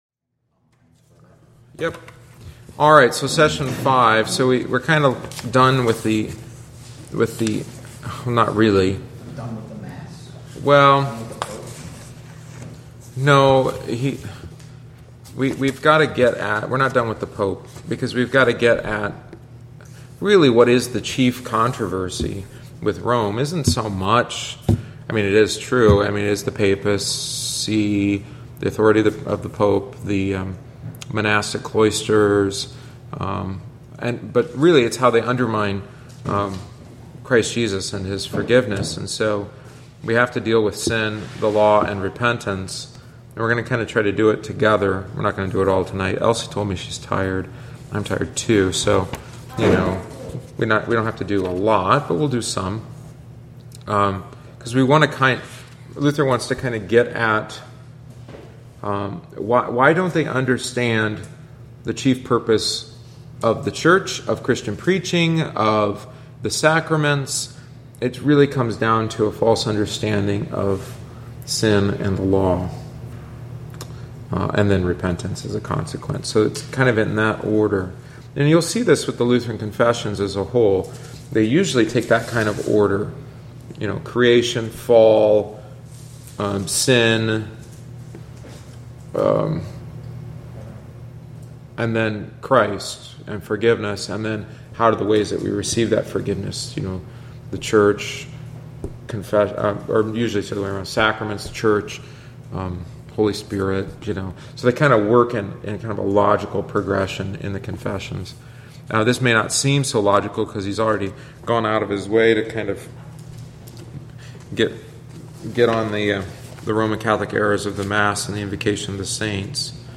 Join us each week on Wednesday after Divine Service (~7:45pm) for aÂ study of one of our Lutheran Confessions, the Smalcald Articles. Written by Martin Luther shortly before his death, it is vivid, to the point, and sometimes cantankerous.